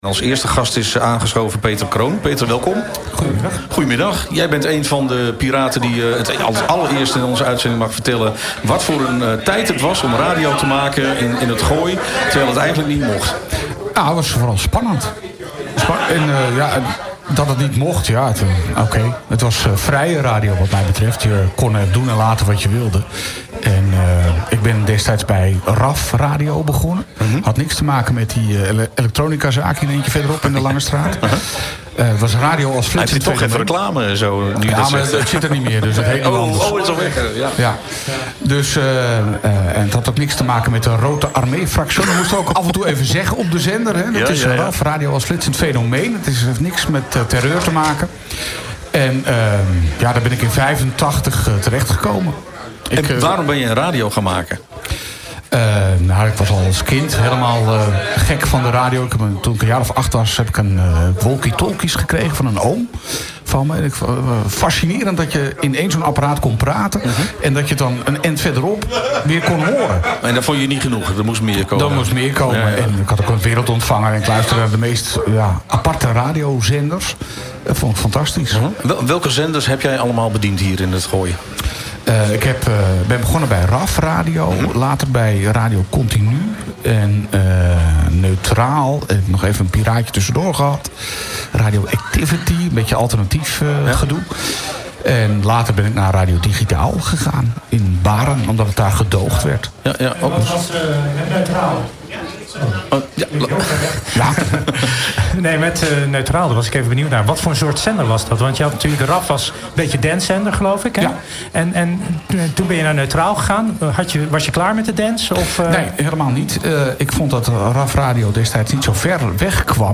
Een interview vanuit de speciale uitzending vanaf de Kerkbrink in Hilversum. In het kader van de Dutch Media Week en 100 Jaar Radio gingen wij terug in de tijd naar de momenten, waar illegale zenders in ‘t Gooi overal de kop op staken en razend populair waren.